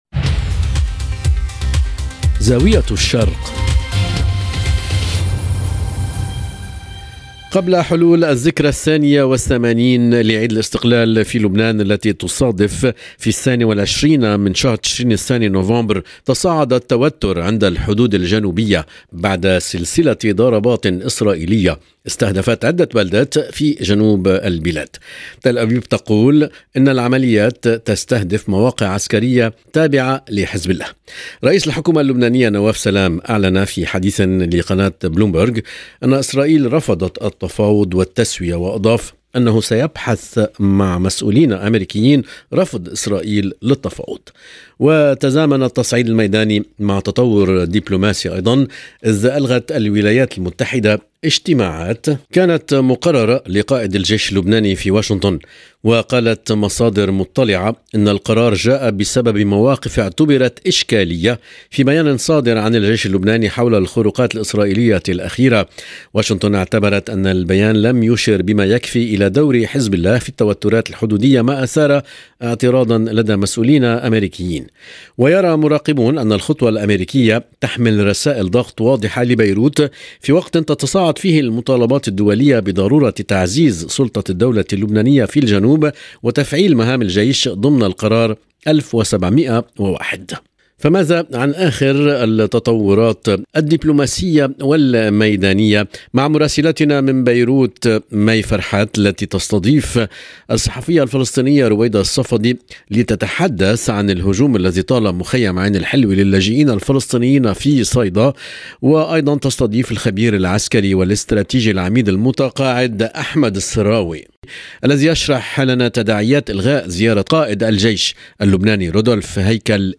وتناول الضيفان أبرز التطورات وتأثيرها على الوضع الداخلي، خصوصاً في ظل الحساسية الأمنية المتصاعدة.